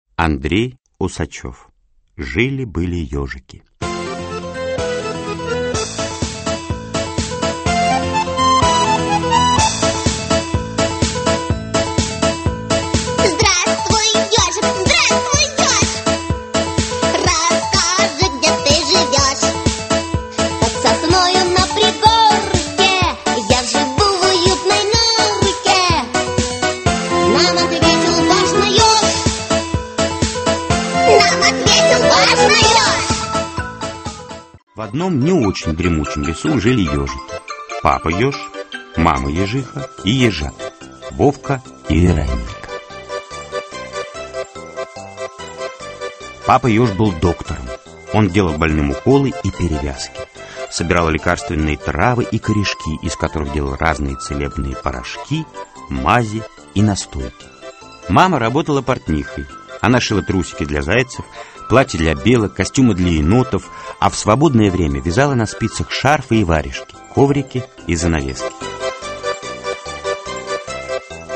Аудиокнига Жили-были ёжики (спектакль) | Библиотека аудиокниг